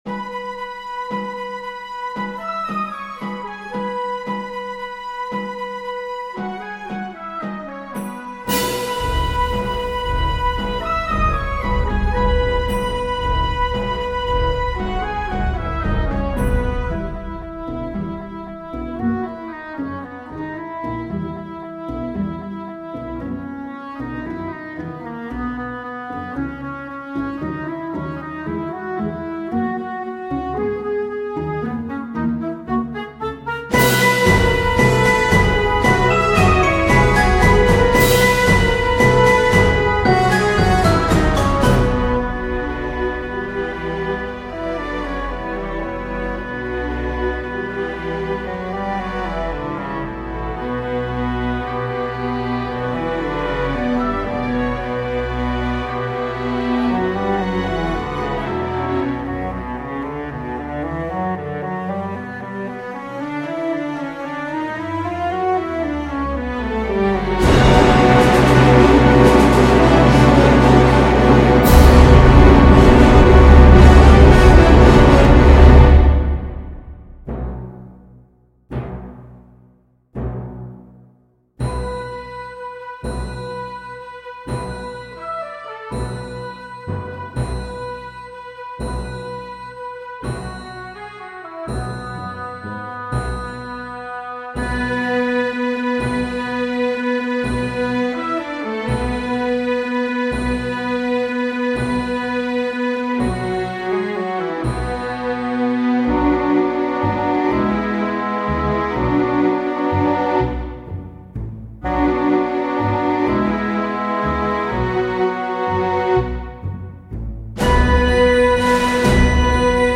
Calutions - Orchestral and Large Ensemble - Young Composers Music Forum